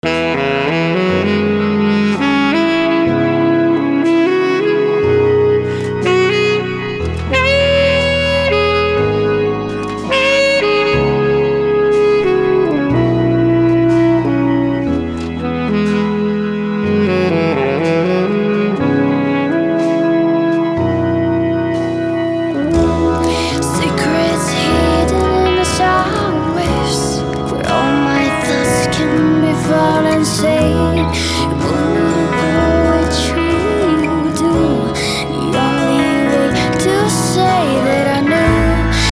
a Macedonian pop rock indie jazz folk supergroup
Lead Vocal
Bass
Saxophone
Drums